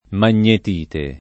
magnetite [ man’n’et & te ] s. f. (min.)